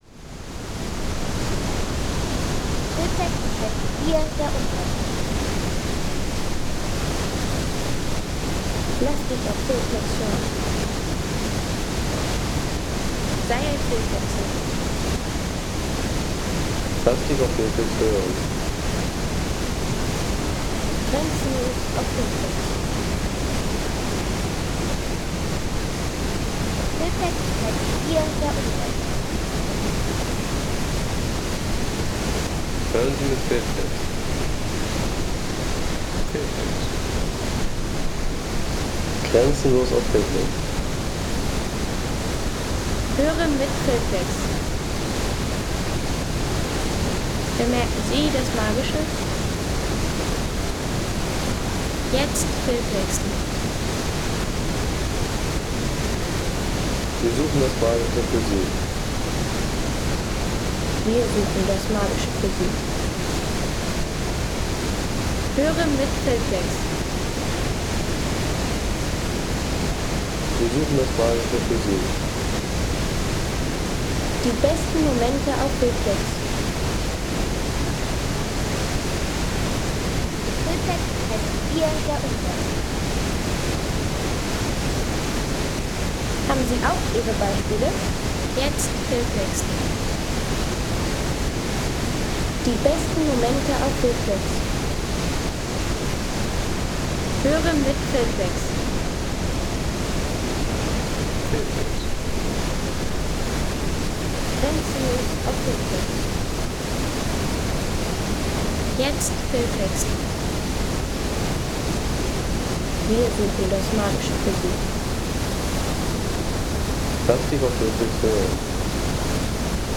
Landschaft - Wasserfälle